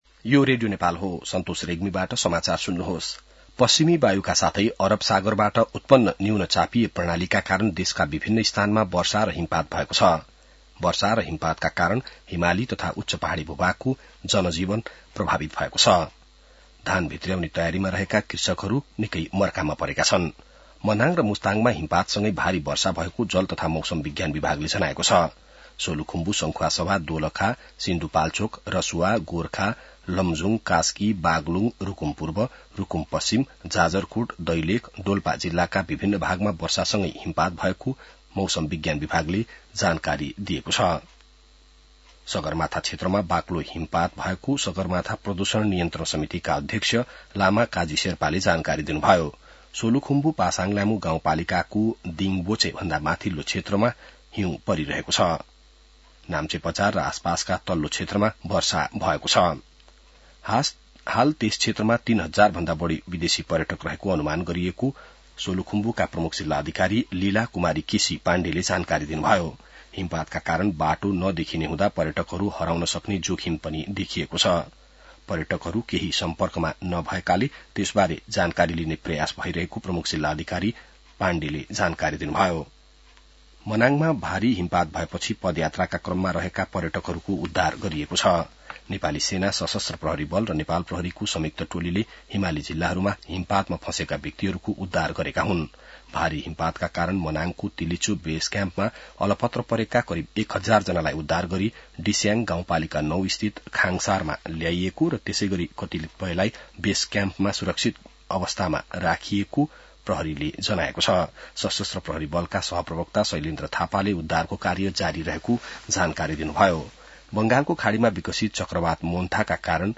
बिहान ६ बजेको नेपाली समाचार : १२ कार्तिक , २०८२